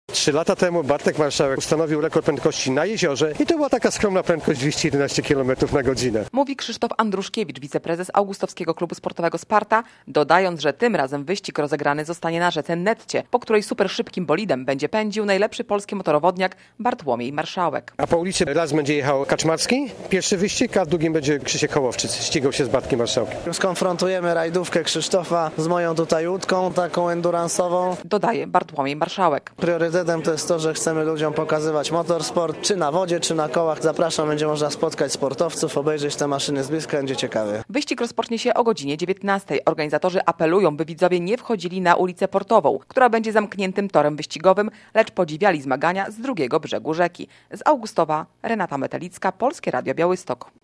Nietypowy wyścig w Augustowie - relacja